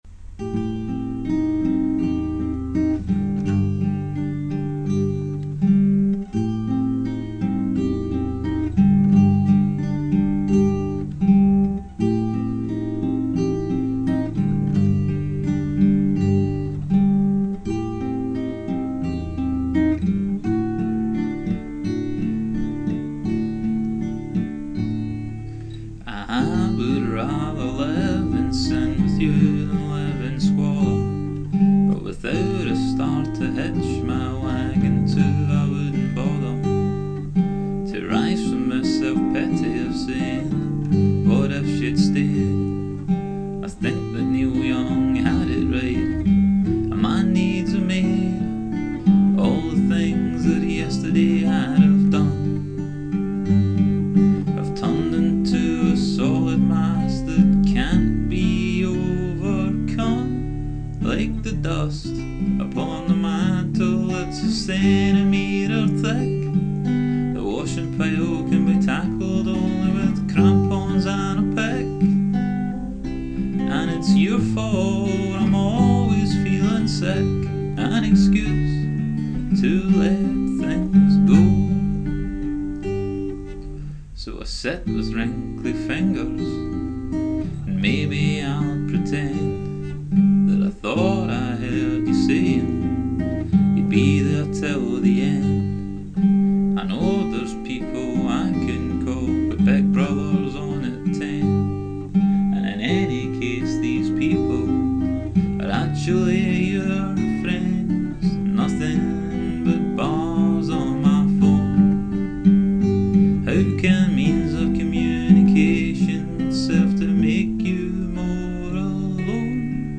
NuJazz